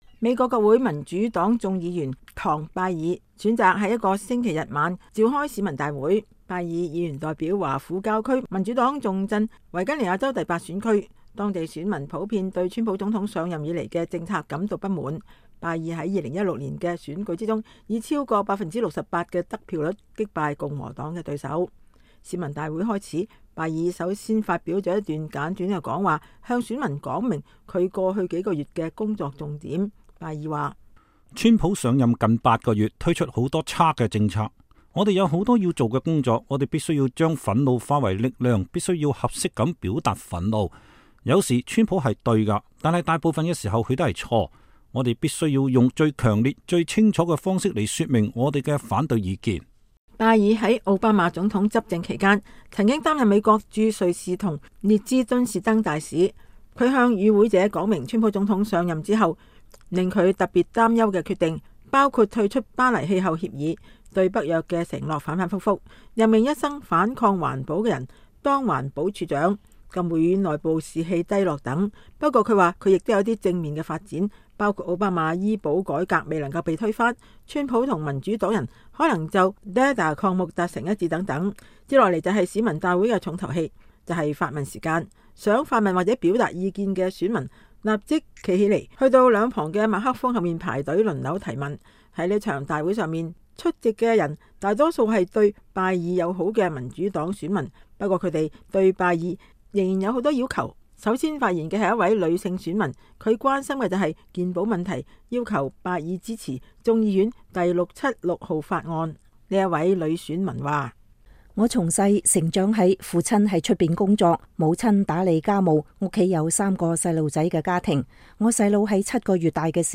維吉尼亞州市民大會 採訪拜爾議員